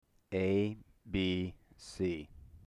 The following is a plot of the digitized recording of me saying
"a b c":